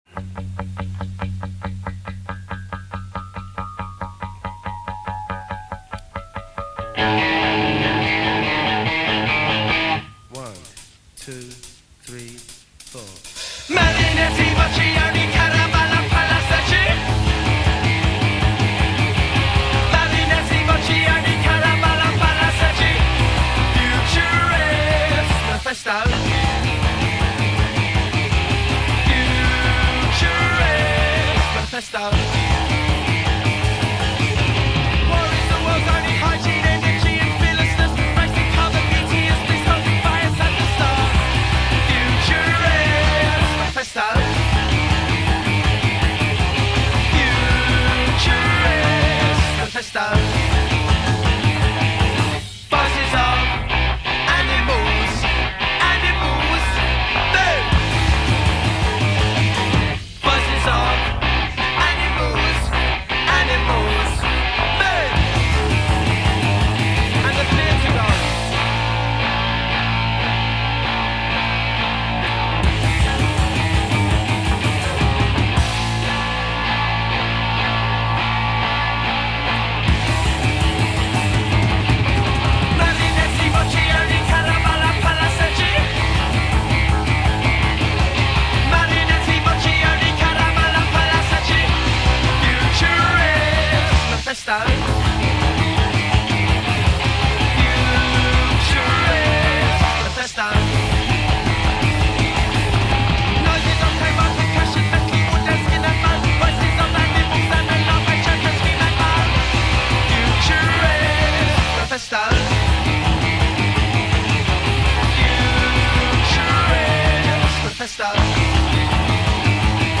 Punk Band
loud, experimental and pretty raw